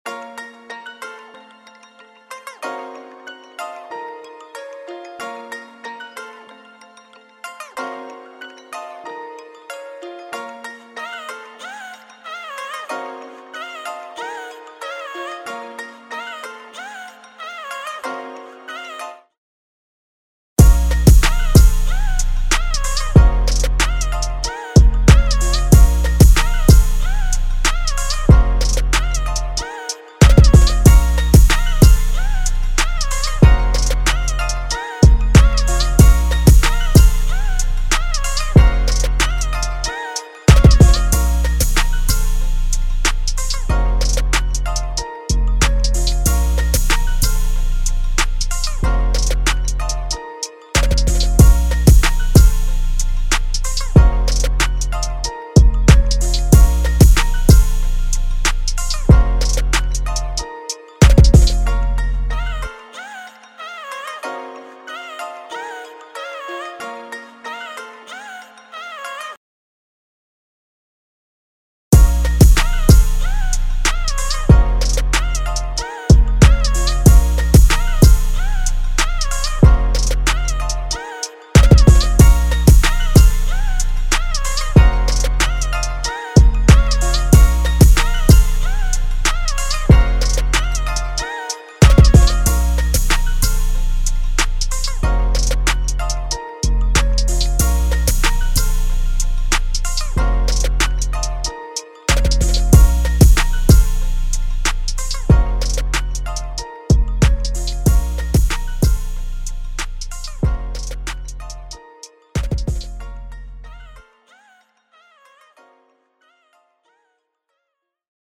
official instrumental
Hip-Hop Instrumentals